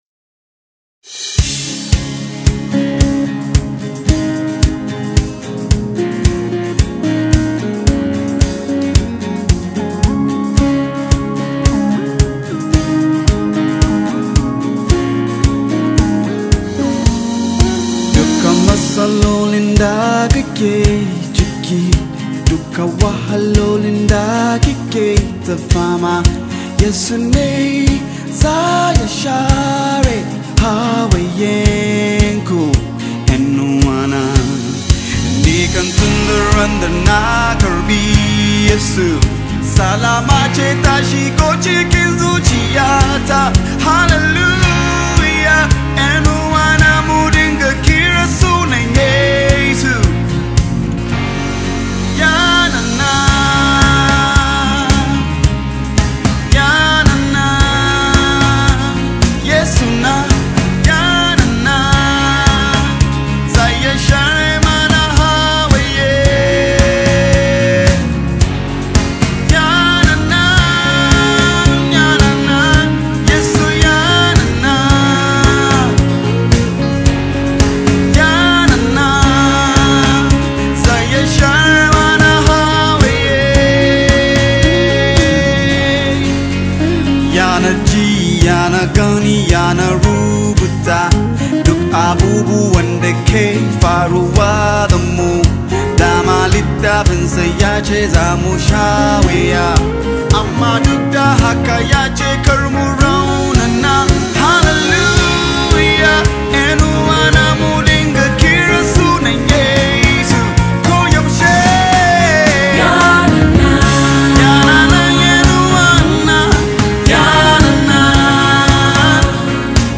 AlbumsMUSICNaija Gospel Songs